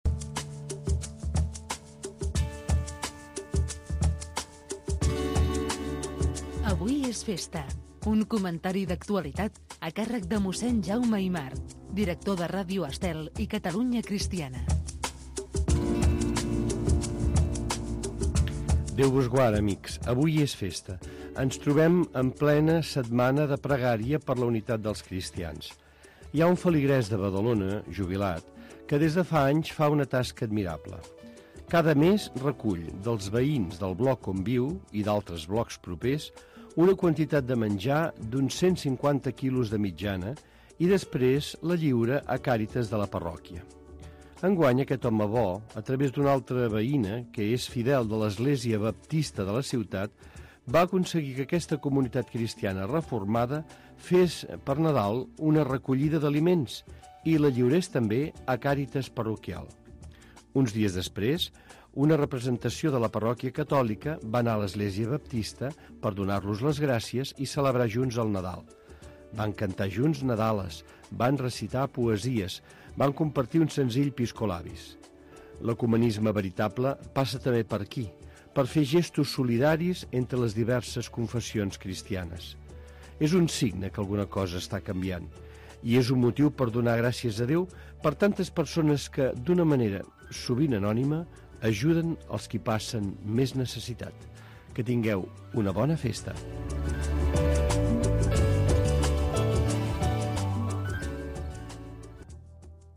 Careta del programa, comentari sobre la setmana de pregària per a la unitat dels cristians amb un exemple de solidaridat a Badalona